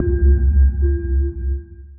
sonarTailSuitFarShuttle2.ogg